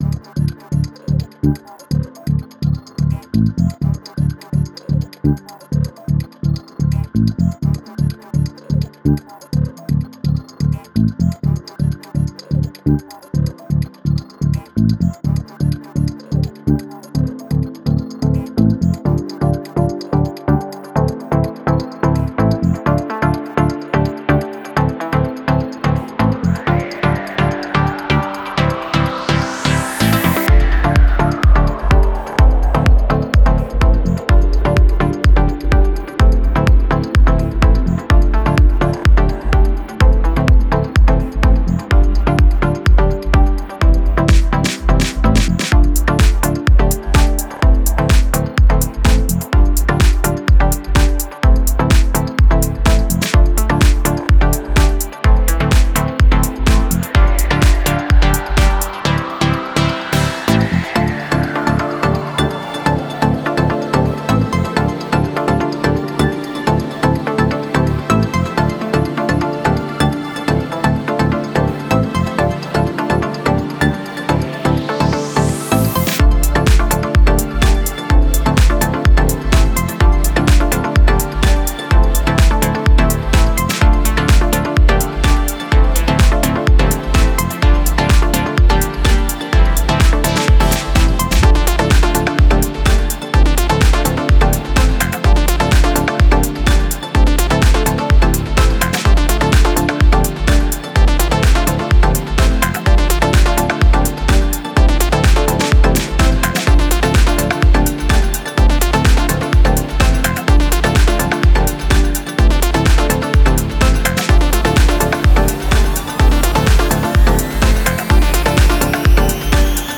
Genre: House , Trance , Spacesynth , Synthpop , Electronic.